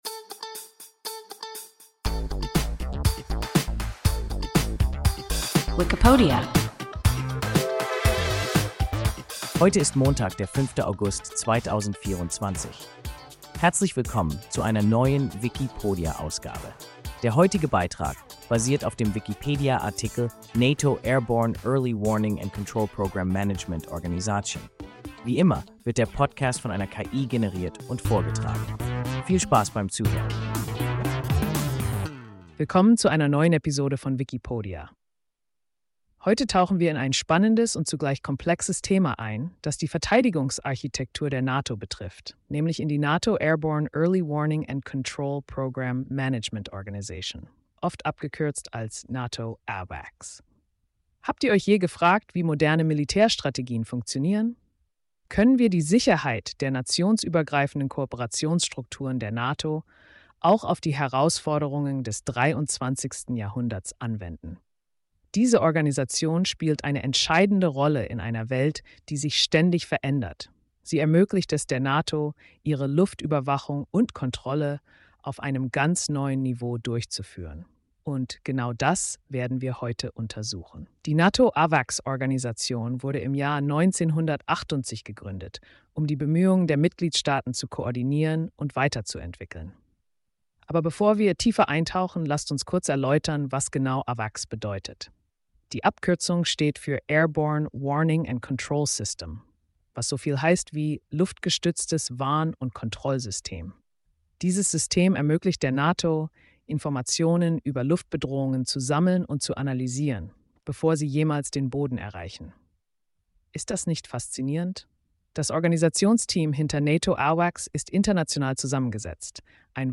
NATO Airborne Early Warning and Control Programme Management Organisation – WIKIPODIA – ein KI Podcast